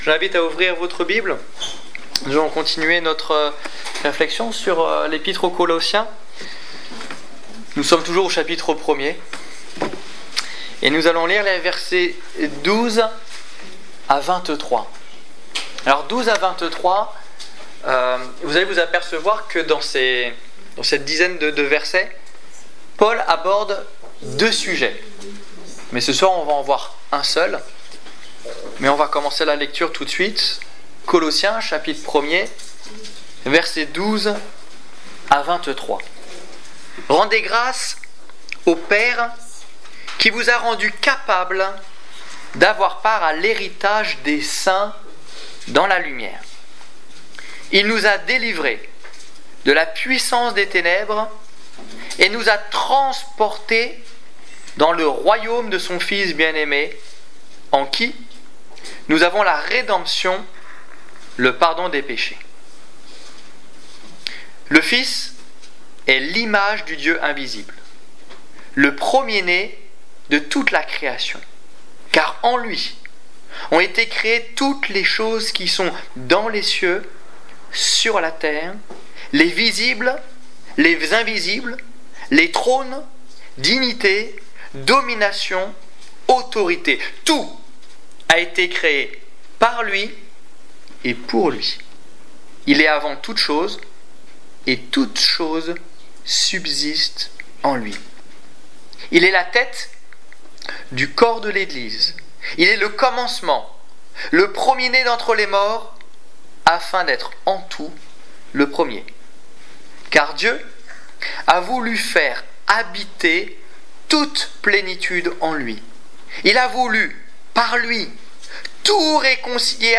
Étude biblique du 24 septembre 2014